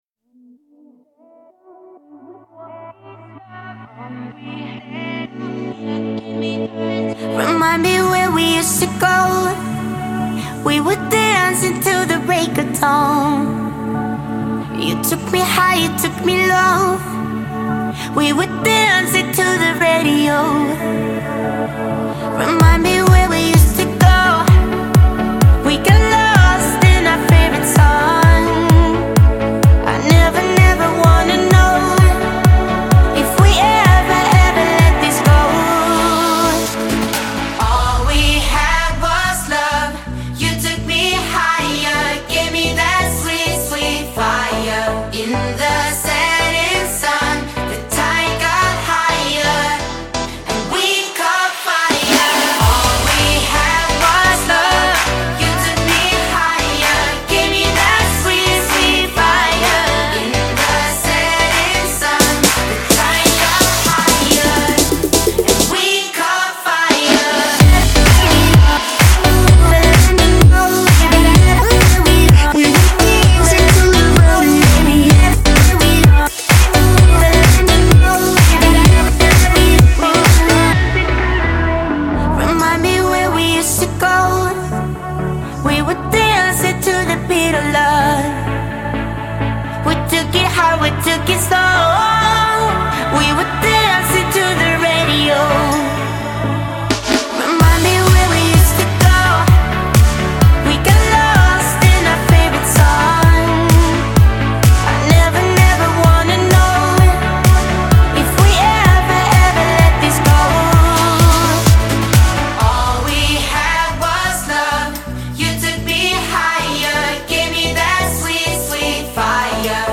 это яркая поп-песня норвежского дуэта